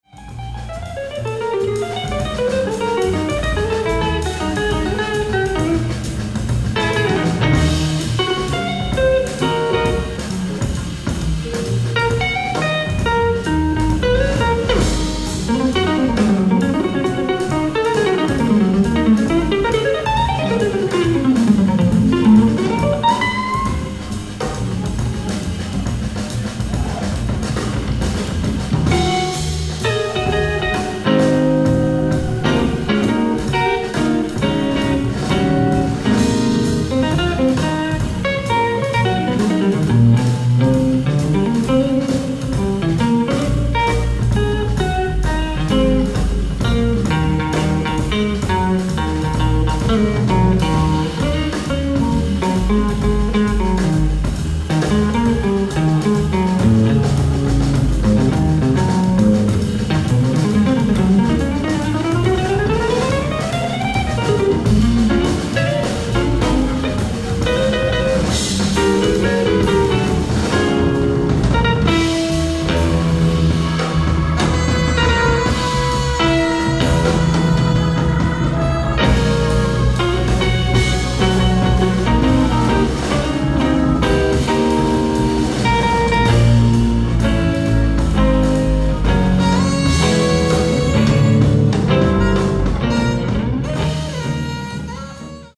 ライブ・アット・リンカーン・シアター、ワシントン D.C. 03/30/2024
※試聴用に実際より音質を落としています。